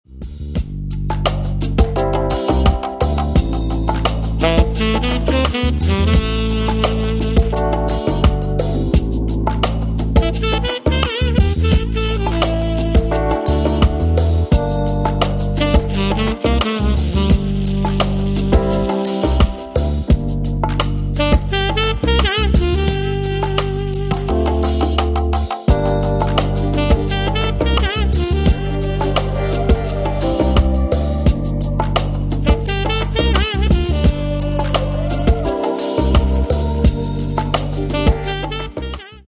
Tenor-Saxophon